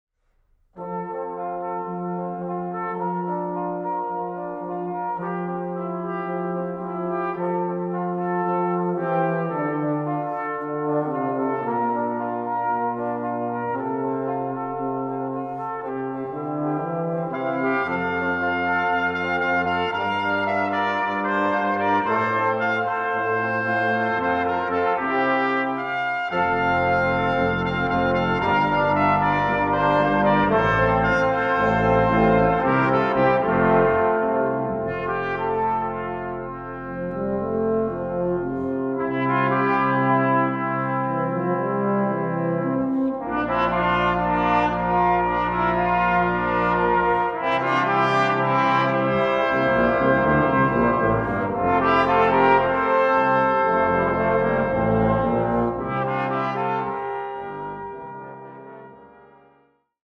Fantasia for brass ensemble